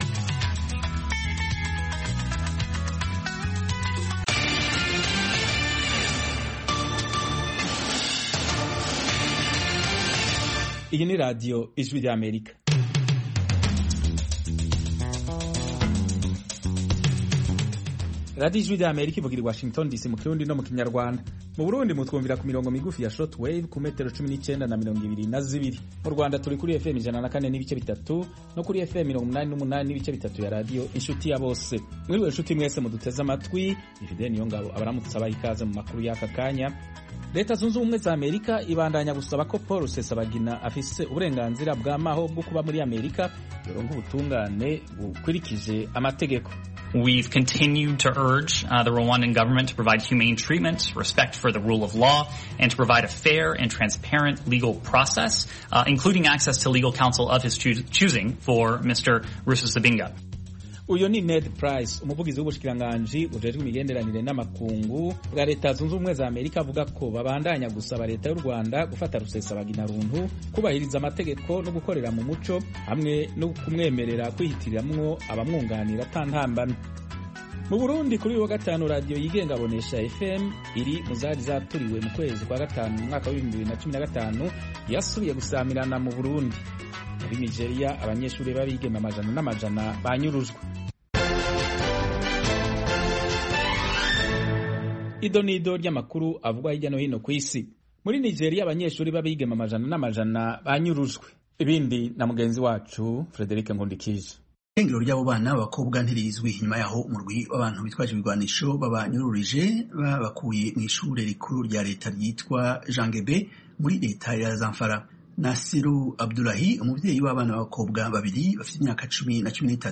Amakuru y'Akarere (1600-1630 UTC): Amakuru atambuka i saa kumi n'ebyeri ku mugoroba mu Rwanda no mu Burundi. Akenshi, aya makuru yibanda ku karere k'ibiyaga bigari n'Afurika y'uburasirazuba.